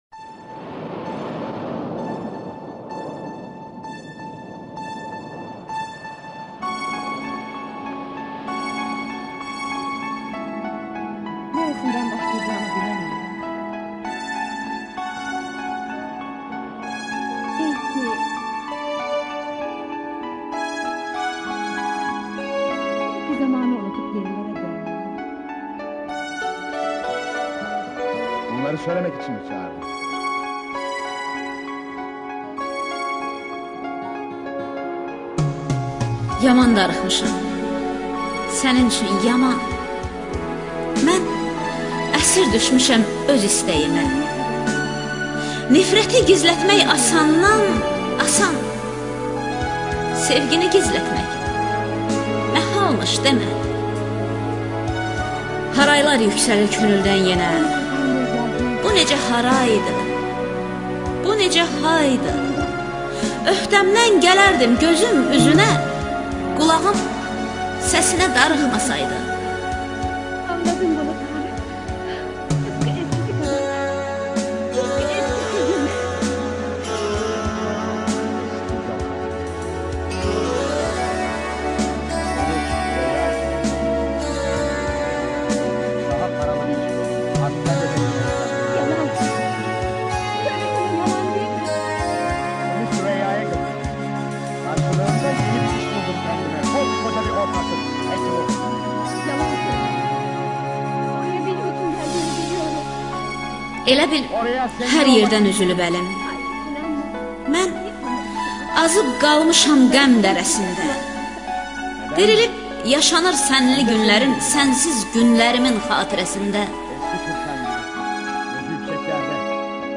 ŞEİRLƏR
AKTYORLARIN İFASINDA